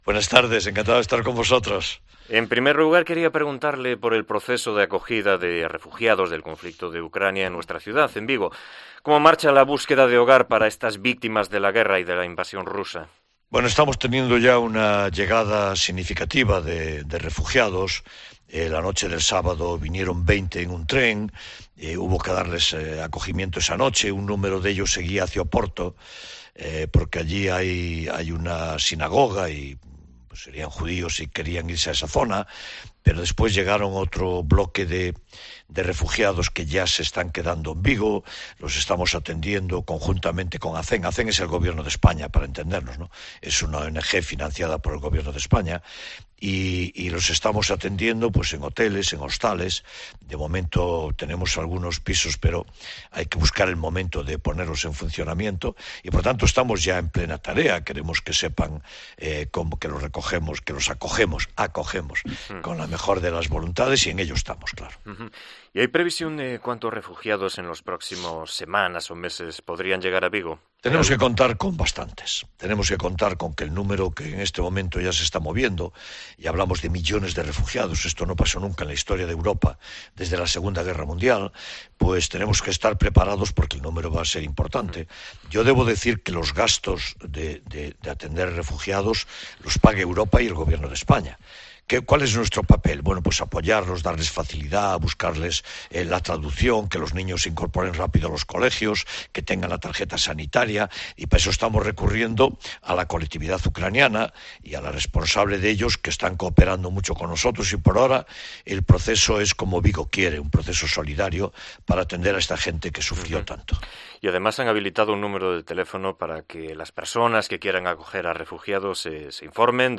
Entrevista con el alcalde de Vigo, Abel Caballero